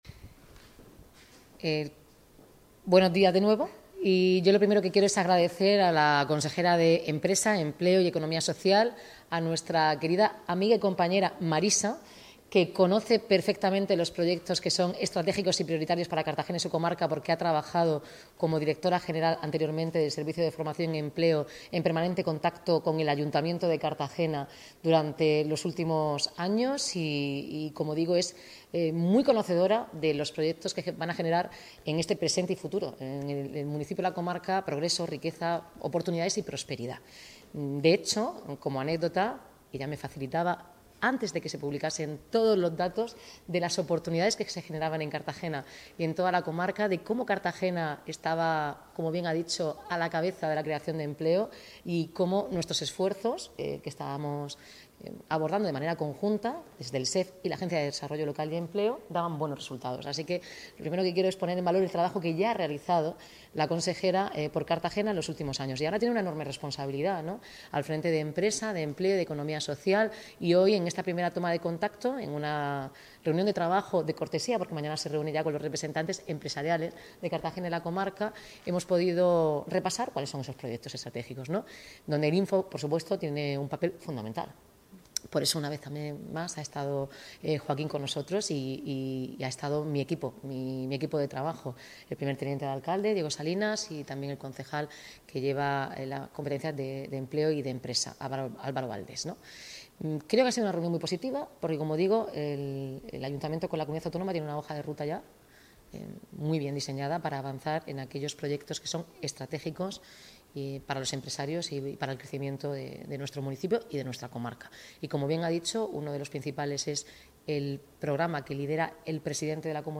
Enlace a Declaraciones de la alcaldesa, Noelia Arroyo, y la consejera de Empresa, Marisa López